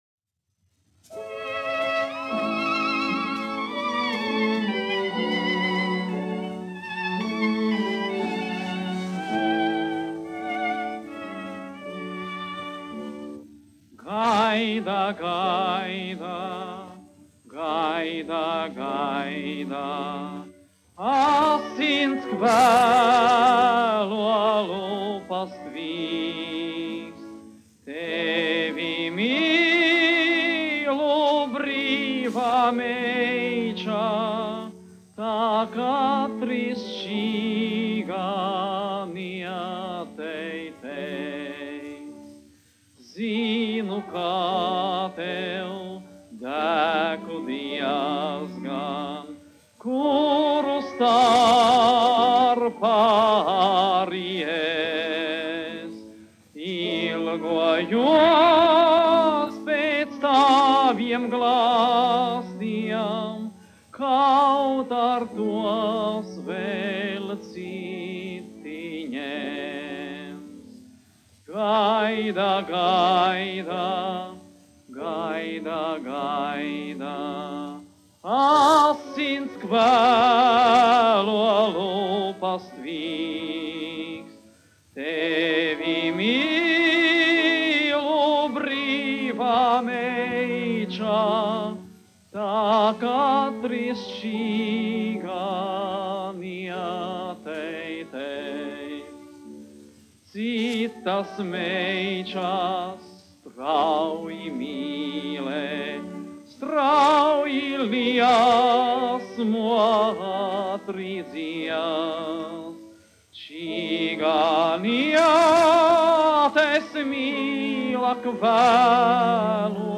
1 skpl. : analogs, 78 apgr/min, mono ; 25 cm
Dziesmas (augsta balss) ar instrumentālu ansambli
Latvijas vēsturiskie šellaka skaņuplašu ieraksti (Kolekcija)